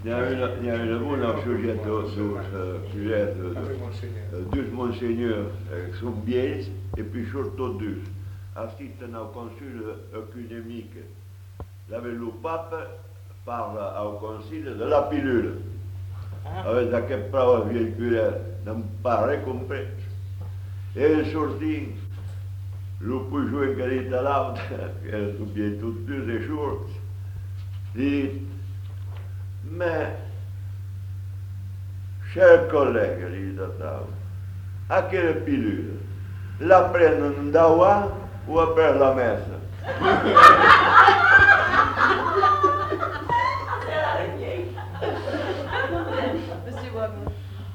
Aire culturelle : Bazadais
Lieu : Castets-en-Dorthe
Genre : conte-légende-récit
Effectif : 1
Type de voix : voix d'homme
Production du son : parlé